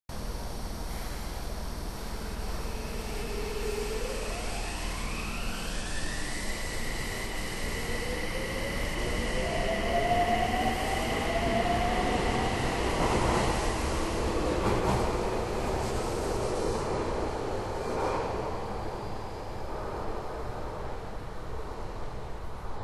6300形電車 加速音三田線
内幸町駅
初期型と比べると静かになった。最初の小刻みなうなり音が特徴。